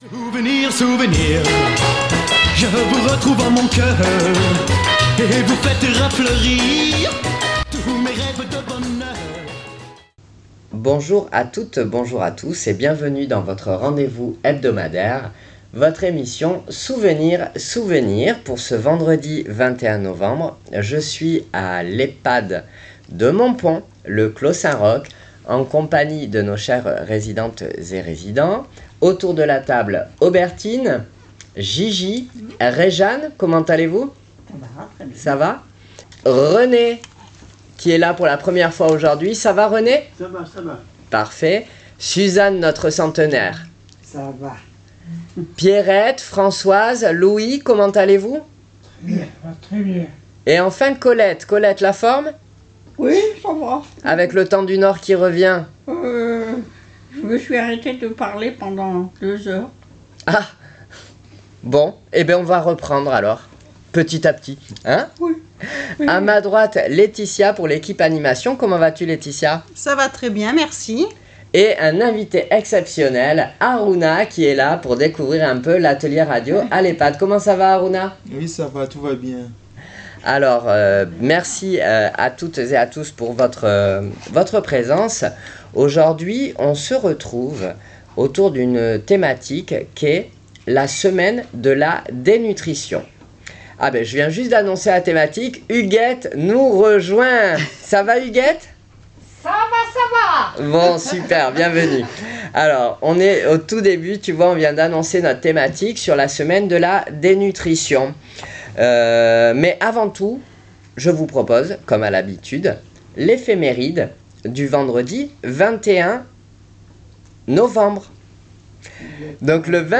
à l'Ehpad de Montpon, Le Clos Saint Roch, avec nos chers résidents en pleine forme